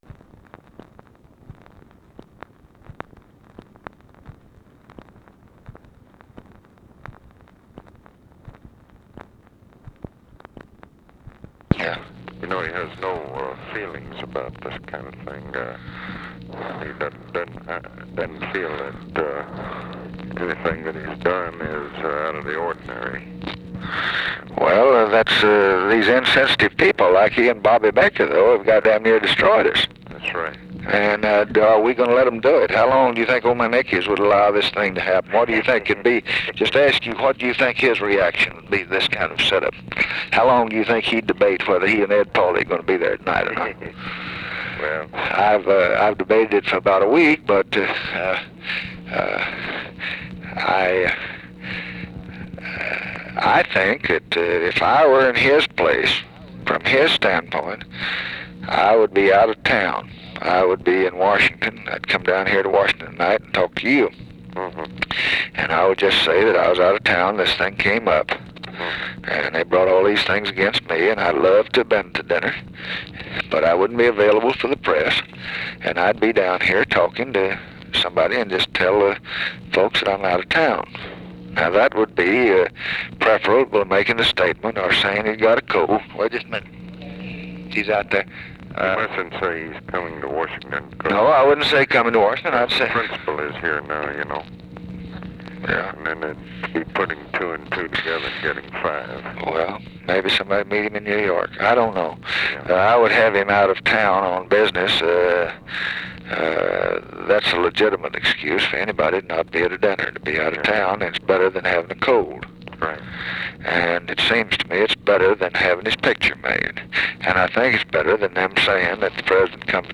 Conversation with ABE FORTAS and KEN O'DONNELL, September 10, 1964
Secret White House Tapes